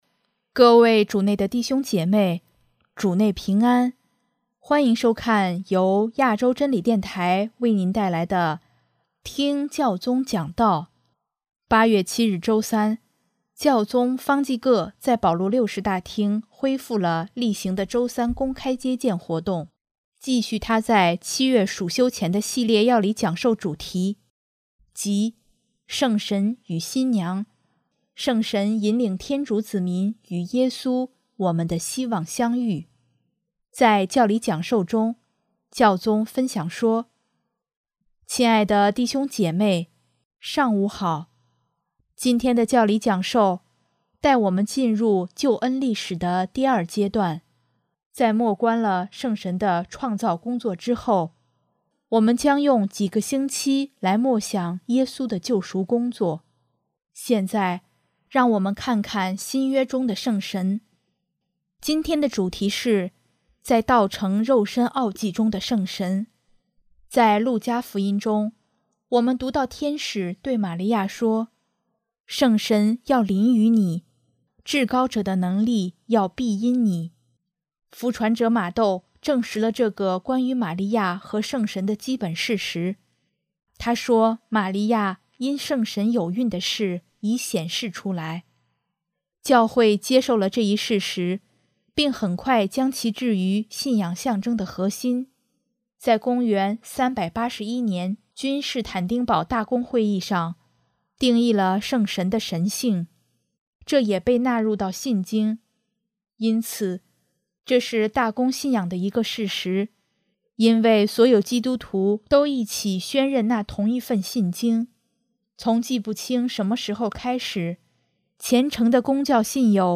【听教宗讲道】|没有圣神，教会无法前进、成长和宣讲
8月7日周三，教宗方济各在保禄六世大厅恢复了例行的周三公开接见活动，继续他在7月暑休前的系列要理讲授主题，即“圣神与新娘，圣神引领天主子民与耶稣、我们的希望相遇”。